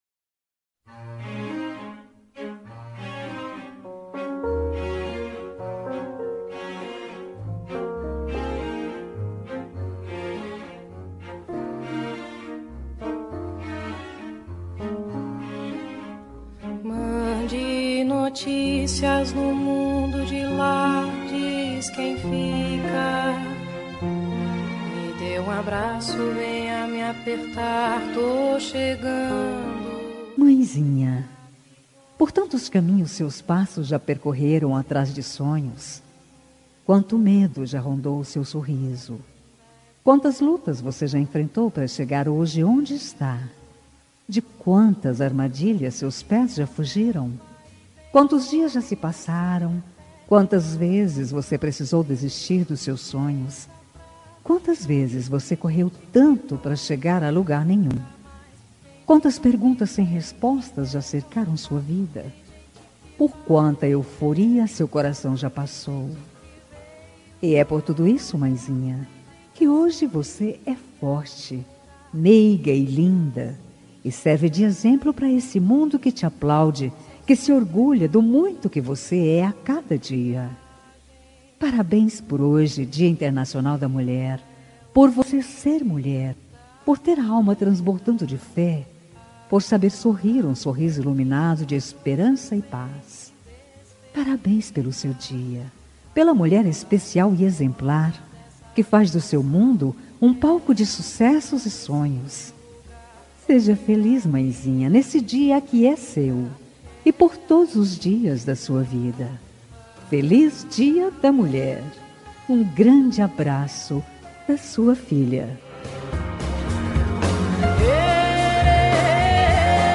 Dia das Mulheres Para Mãe – Voz Feminina – Cód: 5333